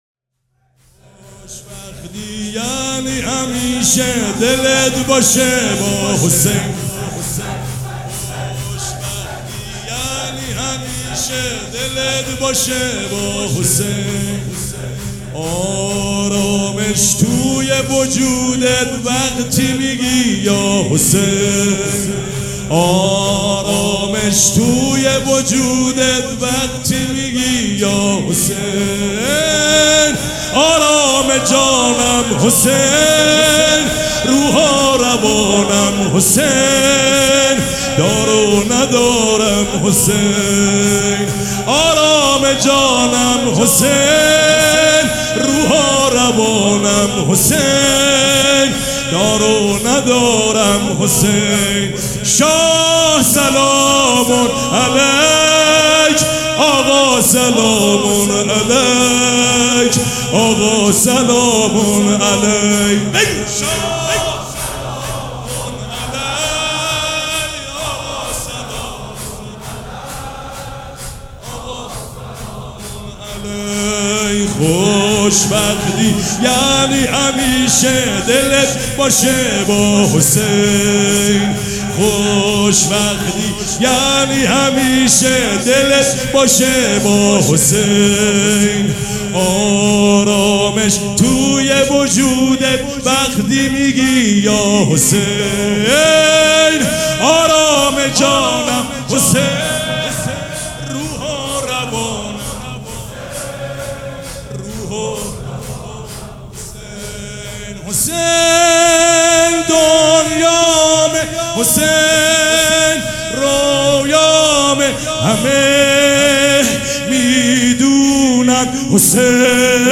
شور
سبک اثــر شور
مراسم عزاداری شب چهارم